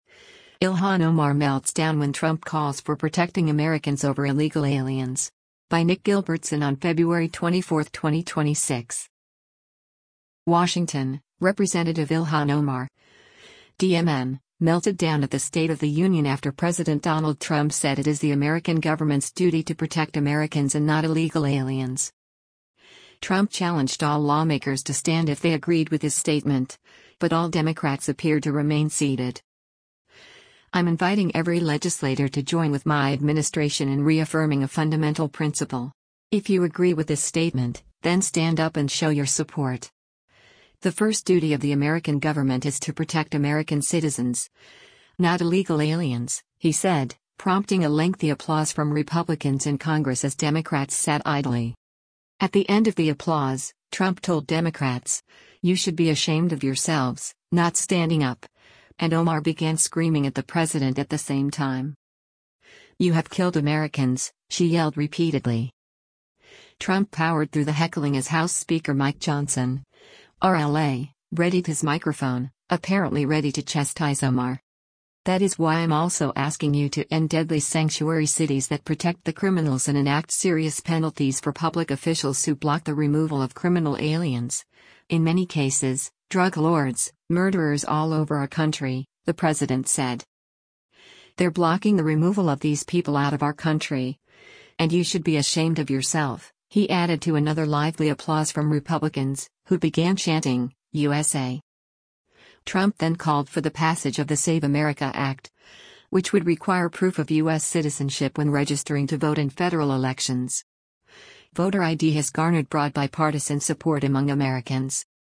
WASHINGTON — Rep. Ilhan Omar (D-MN) melted down at the State of the Union after President Donald Trump said it is the American government’s duty to protect Americans and not illegal aliens.
At the end of the applause, Trump told Democrats, “You should be ashamed of yourselves, not standing up,” and Omar began screaming at the president at the same time.
“You have killed Americans!” she yelled repeatedly.
“They’re blocking the removal of these people out of our country, and you should be ashamed of yourself,” he added to another lively applause from Republicans, who began chanting, “USA!”